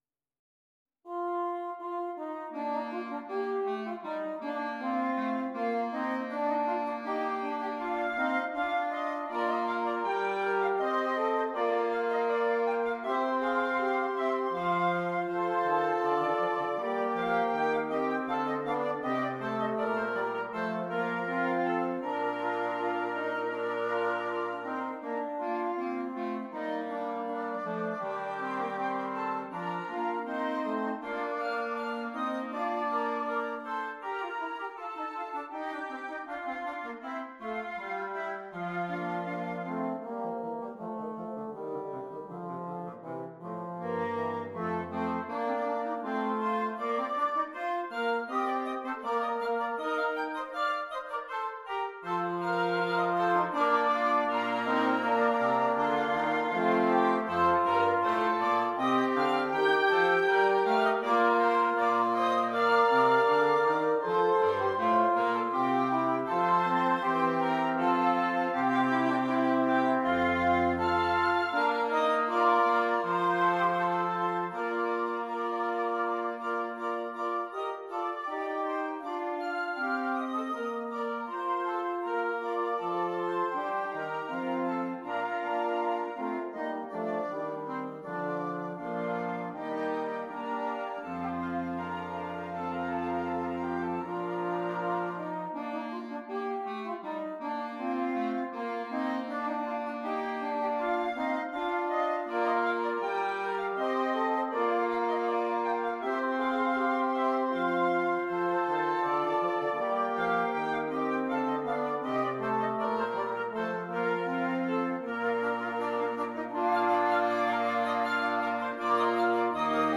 Interchangeable Woodwind Ensemble
PART 1 - Flute, Oboe, Clarinet
PART 3 - Clarinet, Alto Saxophone, F Horn
PART 4 - Clarinet, Alto Saxophone, Tenor Saxophone
PART 5 - Bass Clarinet, Bassoon, Baritone Saxophone